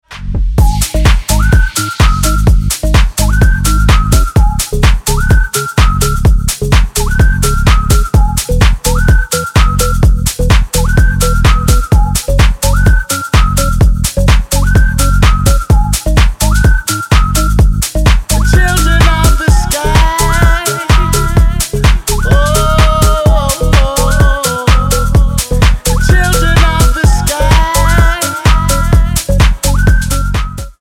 • Качество: 320, Stereo
свист
мужской голос
ремиксы
Стиль: house